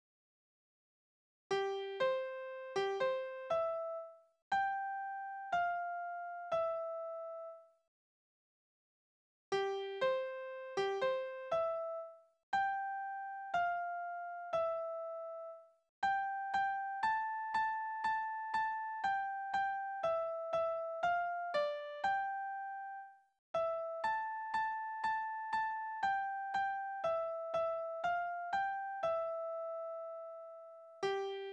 Liebeslieder:
Tonart: C-Dur
Taktart: C (4/4)
Tonumfang: große None
Besetzung: vokal
Vortragsbezeichnung: Marschtempo.